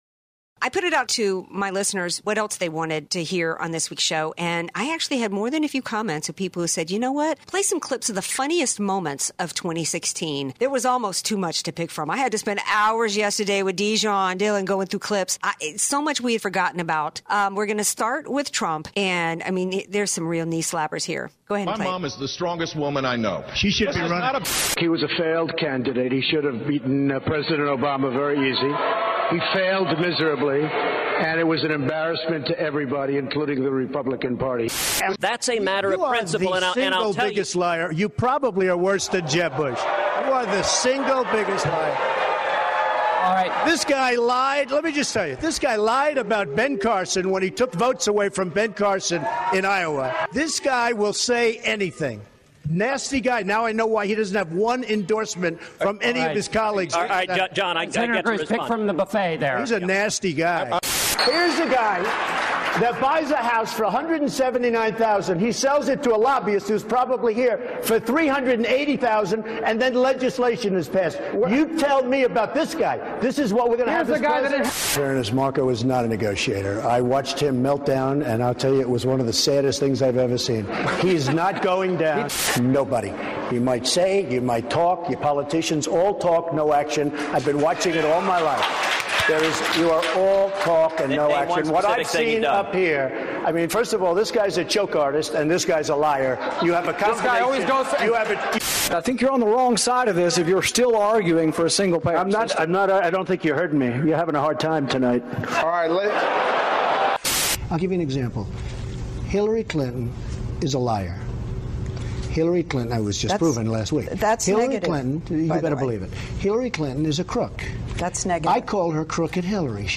Here is the BEST of Trump and the BEST of Hillary.  Her “best” was so much less than his, that it was 1 and 1/2 minutes compared to his 4.